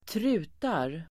Ladda ner uttalet
Folkets service: truta truta verb, pout Grammatikkommentar: A & med x Uttal: [²tr'u:tar] Böjningar: trutade, trutat, truta, trutar Definition: skjuta fram och runda läpparna Exempel: hon trutade med munnen (she pouted)